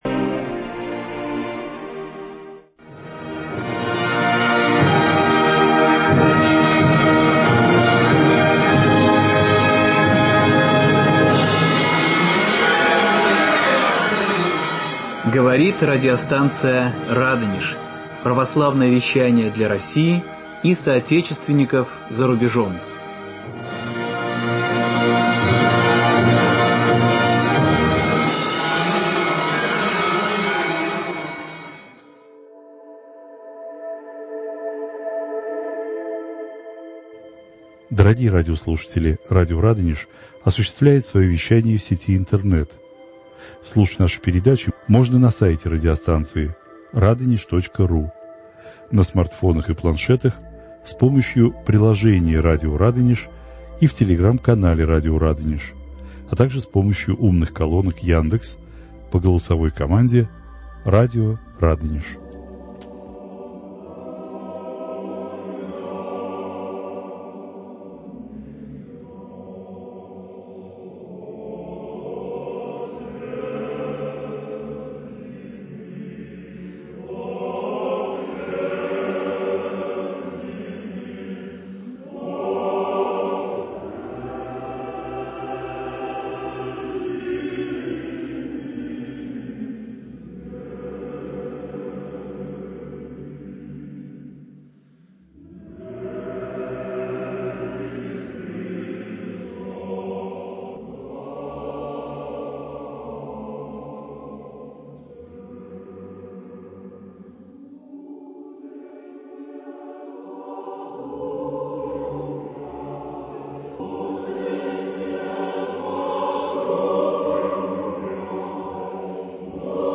На вопросы ведущей отвечают